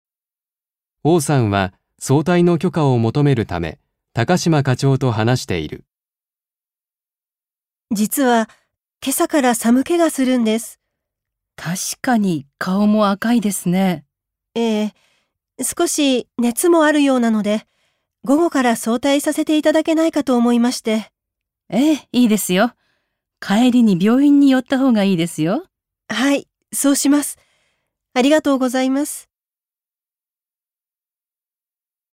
1.2 会話（許可きょかもとめる・許可きょかをする② ）